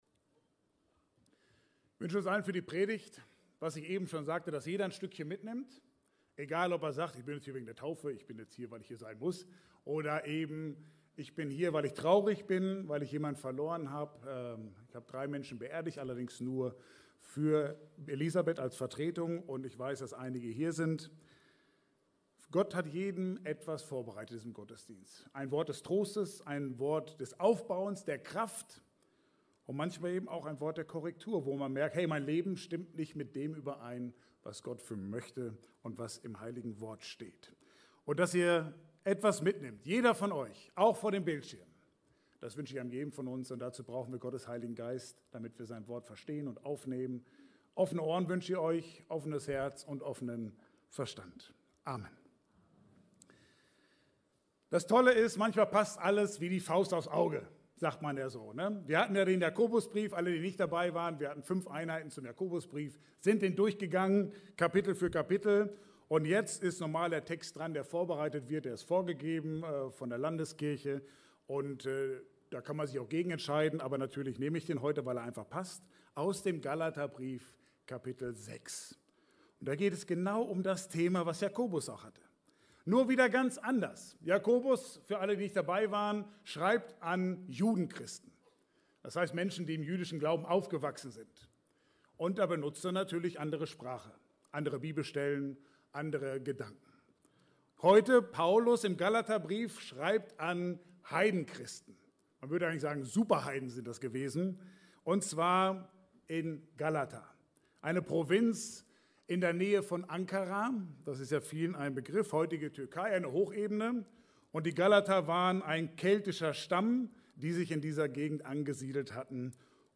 Passage: Galater 5,25–6,10 Dienstart: Gottesdienst